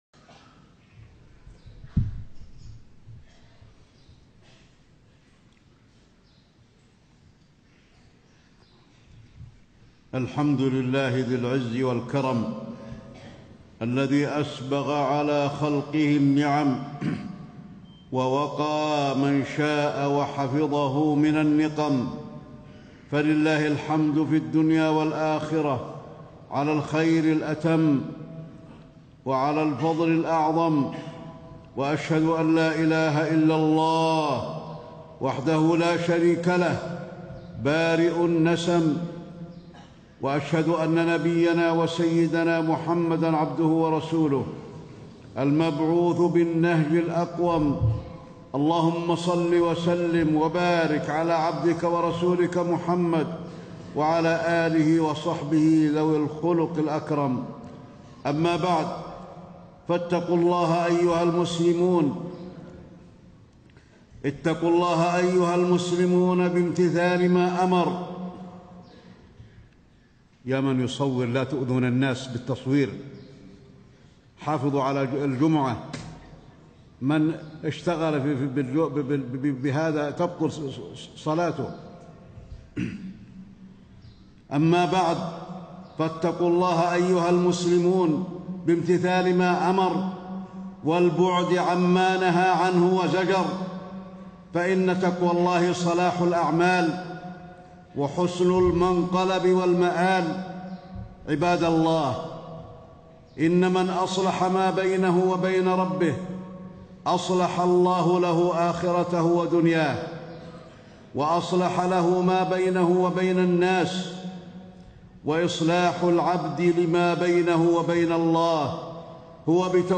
تاريخ النشر ٢٨ ربيع الثاني ١٤٣٥ هـ المكان: المسجد النبوي الشيخ: فضيلة الشيخ د. علي بن عبدالرحمن الحذيفي فضيلة الشيخ د. علي بن عبدالرحمن الحذيفي ولاية الله لعباده